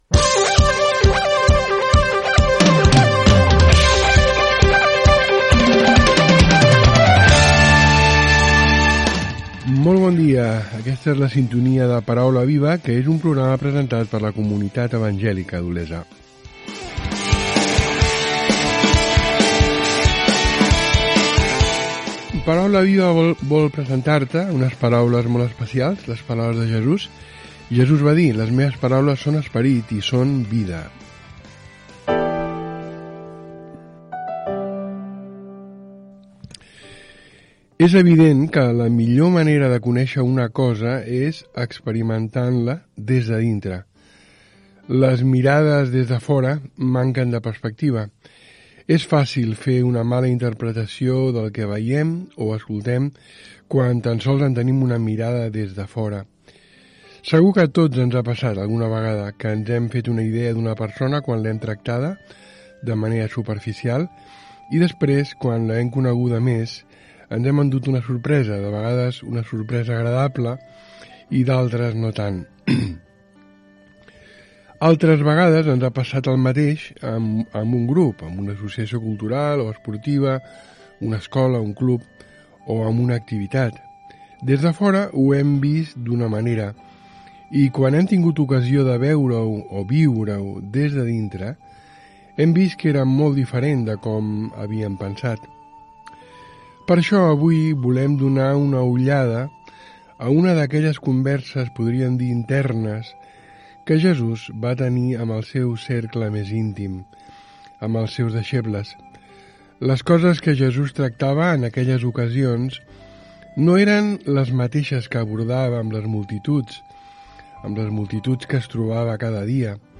Fragment d'una emissió de la comunitat evangèlica d'Olesa de Montserrat.
Religió